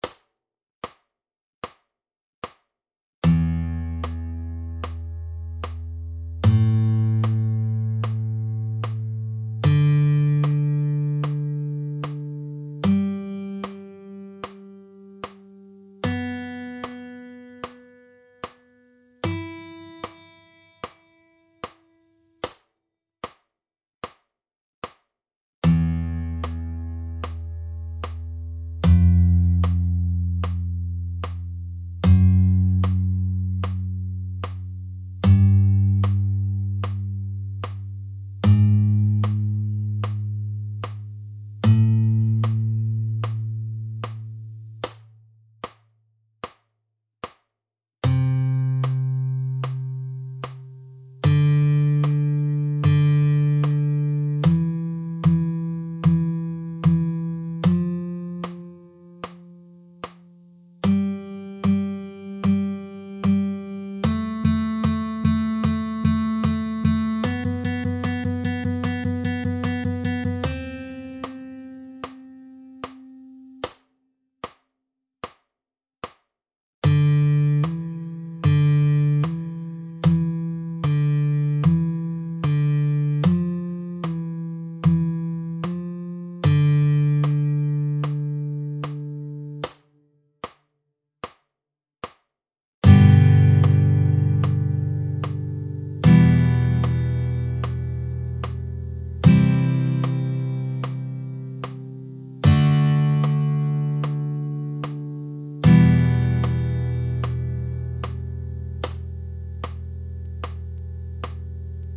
2-How-To-Read-Guitar-Tab-Backing-Track.mp3